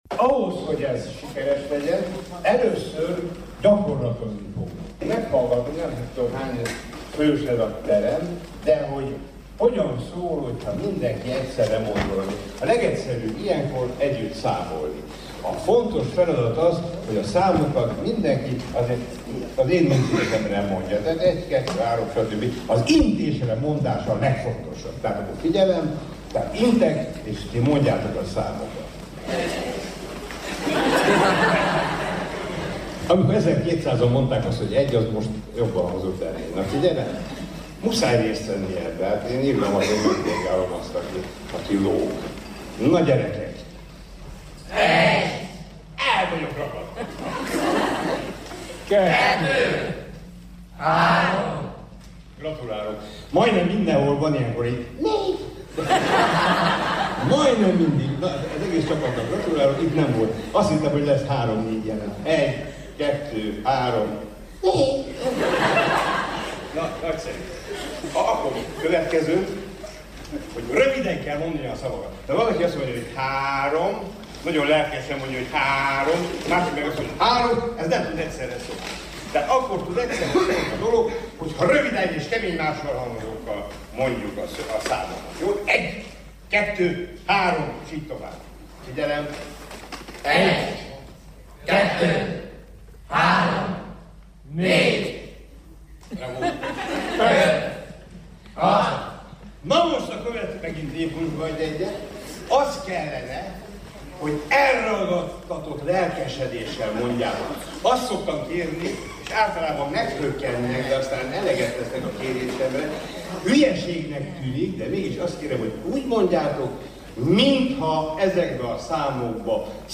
Jordán Tamás legutóbb a Bolyai Farkas Líceum vendége volt a Nagy Versmondással Sebő Ferenc és Fűzfa Balázs társaságában.